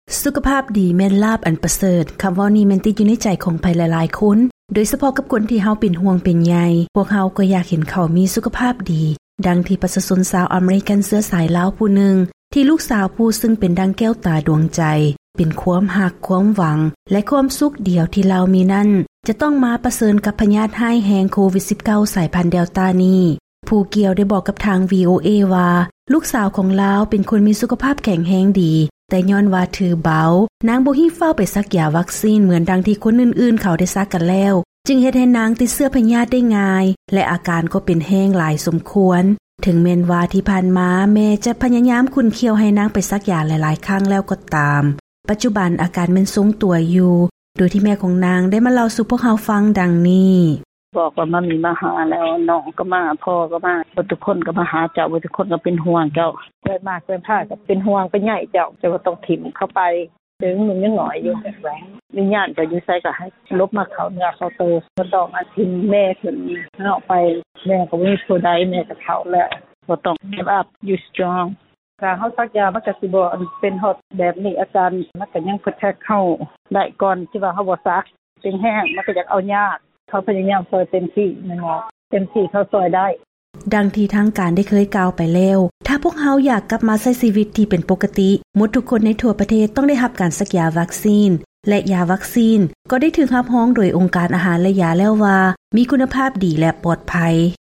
ເຊີນຟັງລາຍງານຂ່າວ ປະໂຫຍດຂອງການສັກຢາວັກຊີນປ້ອງກັນໂຄວິດ-19